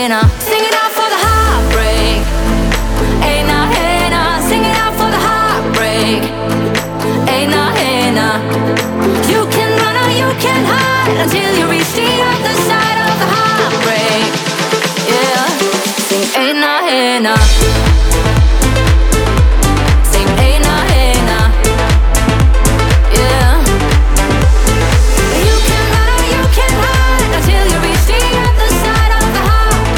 Скачать припев
2025-06-27 Жанр: Поп музыка Длительность